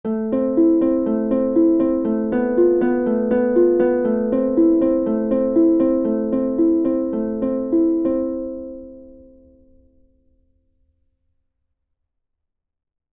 P-I-M-I 2X Arpeggio | Use p-i-m-i on the treble strings only.
Study9_pimi_arpeggio.mp3